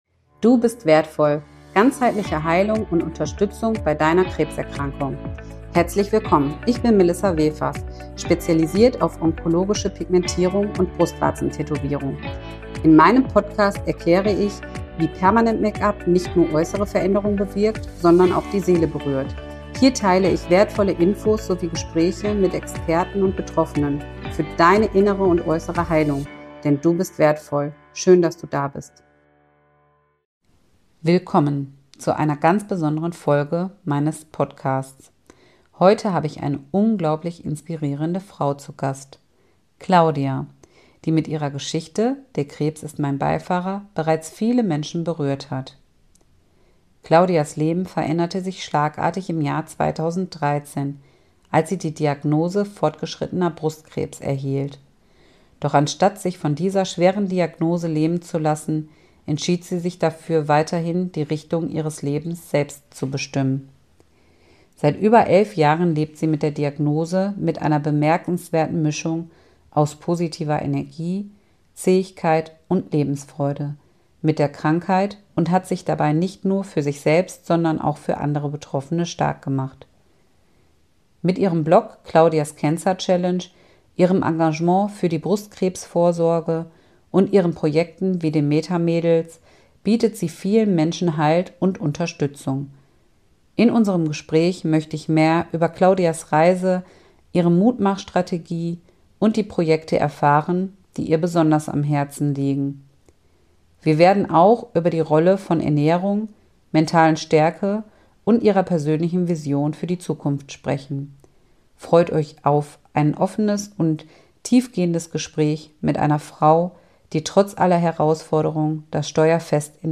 Im Interview gibt sie wertvolle Tipps zur Ernährung und Gesundheit in dieser herausfordernden Lebenslage.